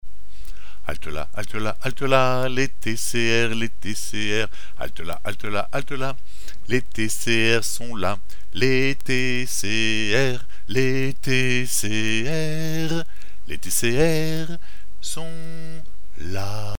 Chansons traditionnelles et populaires
Pièce musicale inédite